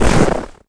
HauntedBloodlines/STEPS Snow, Run 24.wav at main
STEPS Snow, Run 24.wav